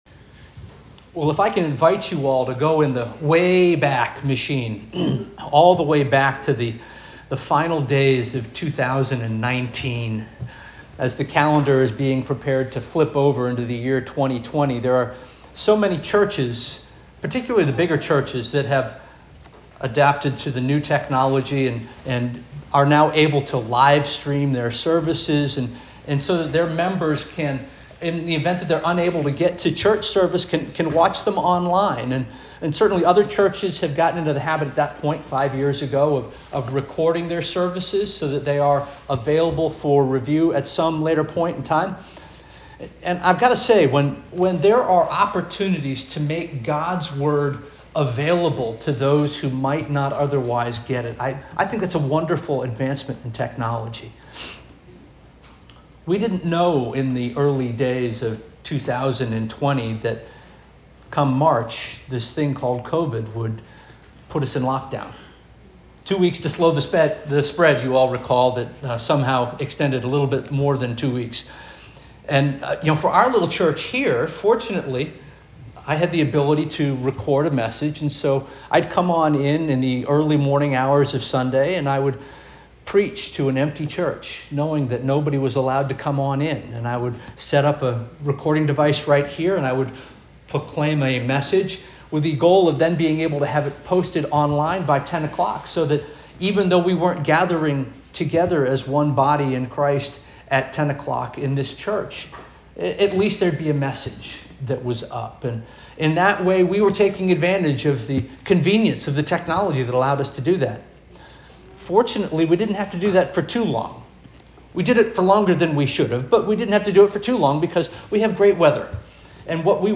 September 7 2025 Sermon - First Union African Baptist Church